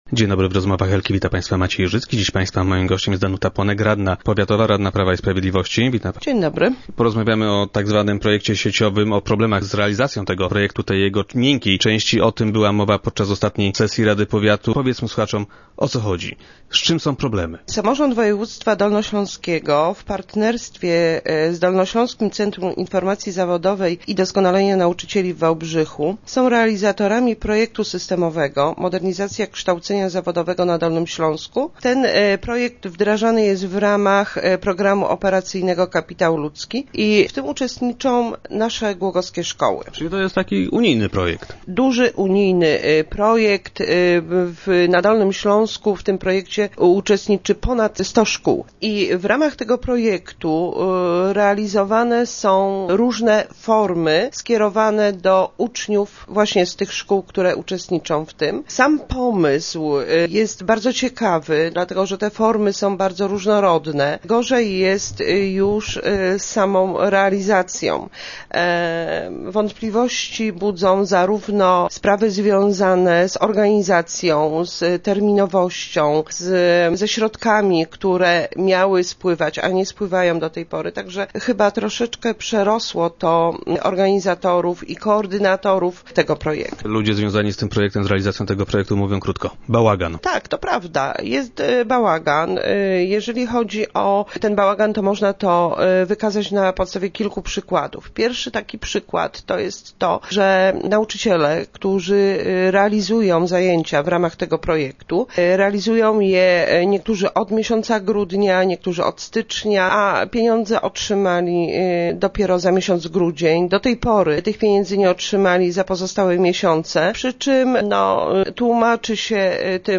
Danuta Płonek była dziś gościem Rozmów Elki.
Podczas audycji, radna Płonek poparła to wieloma przykładami.